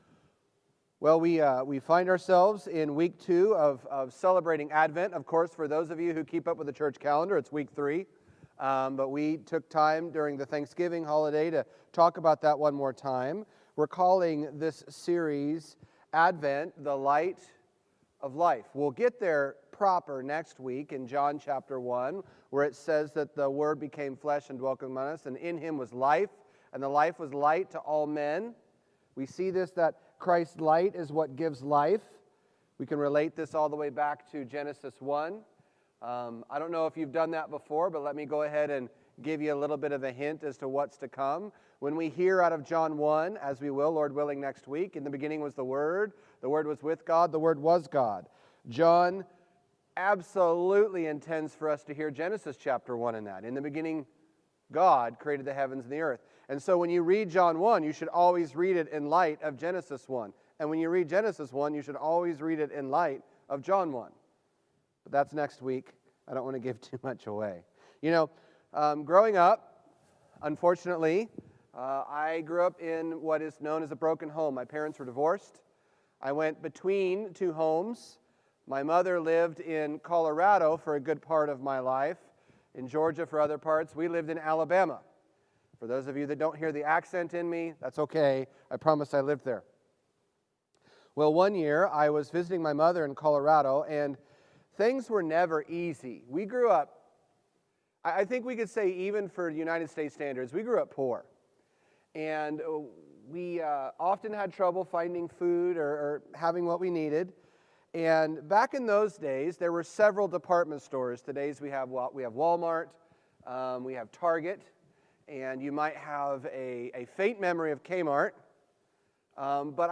A Light for Anyone Preacher